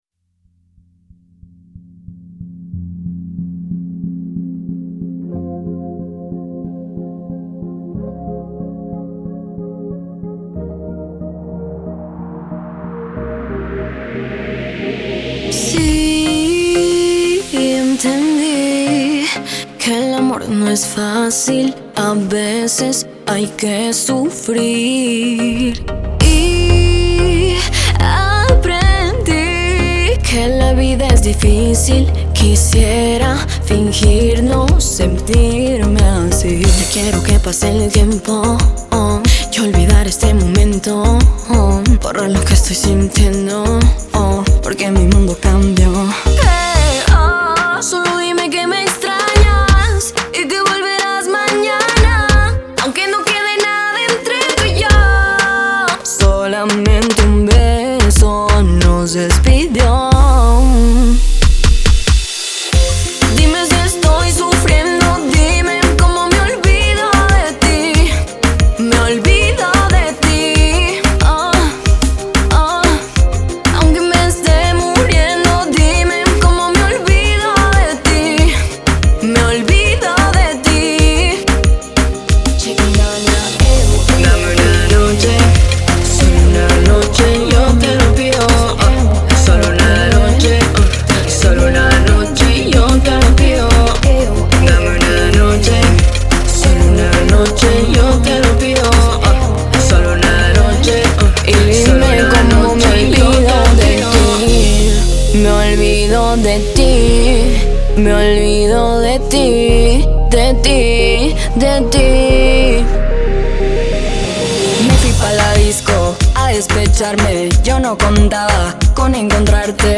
Con un ritmo pop latino que combina vibraciones americanas